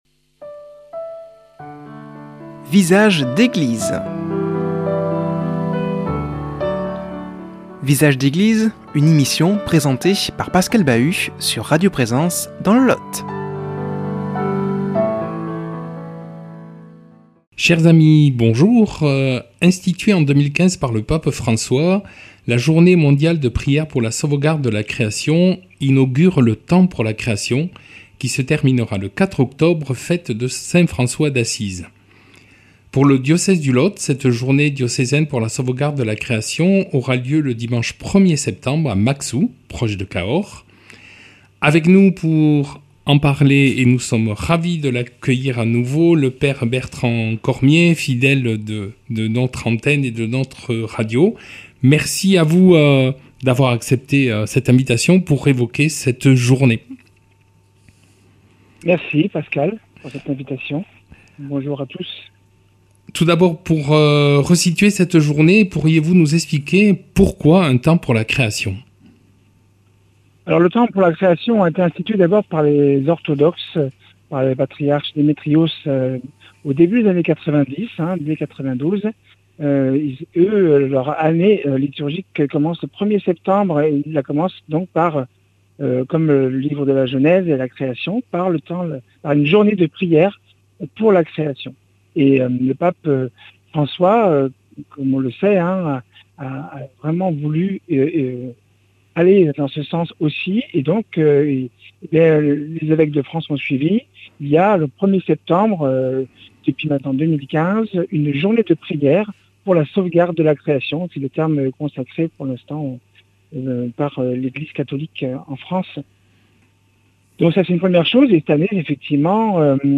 invité par téléphone